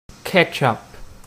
Uttal
US: IPA : [ˈkɛtʃ.əp]